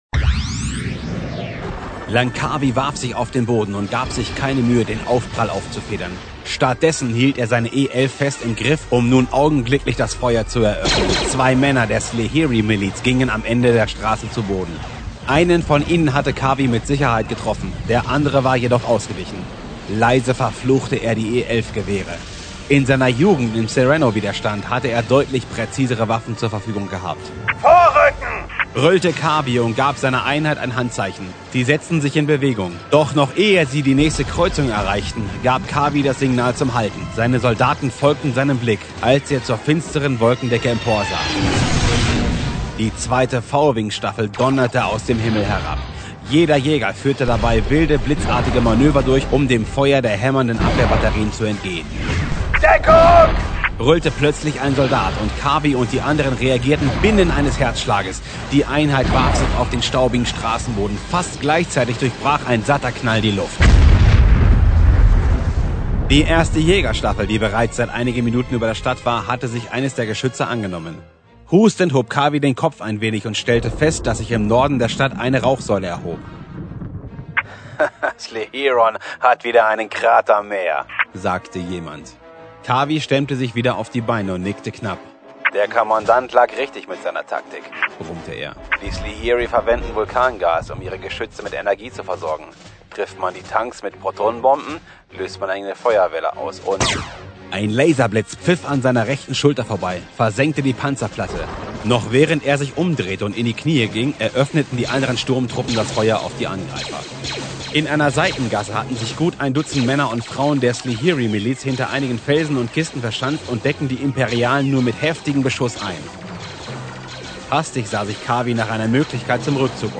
Ich spreche mit einen warmen, seichten Ton, kann aber auch in den Bass Bereich abtauchen.
Freundfeuer – Star Wars Hörbuch 2009